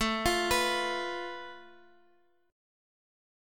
Listen to Asus2#5 strummed